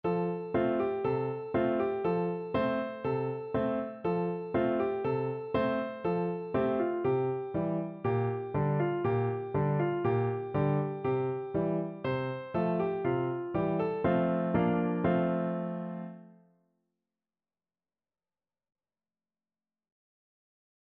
Piano version
No parts available for this pieces as it is for solo piano.
4/4 (View more 4/4 Music)
Allegro (View more music marked Allegro)
Piano  (View more Easy Piano Music)
world (View more world Piano Music)